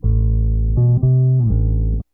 BASS 8.wav